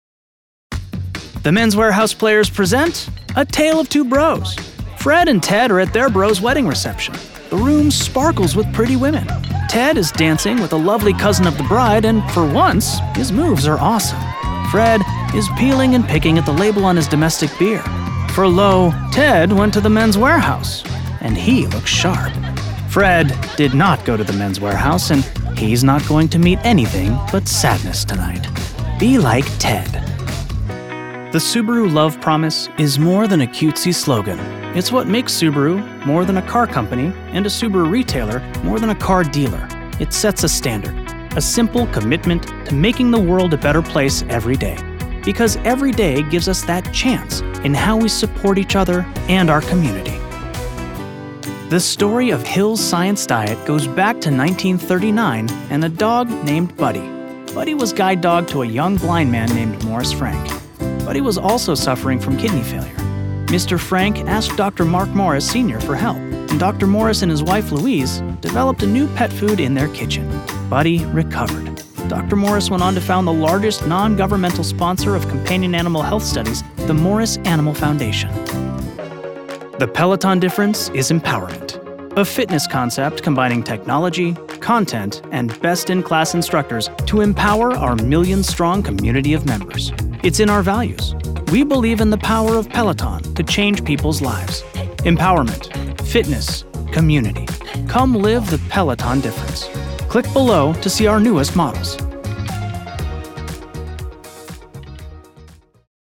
Never any Artificial Voices used, unlike other sites.
Explainer & Whiteboard Video Voice Overs
Yng Adult (18-29) | Adult (30-50)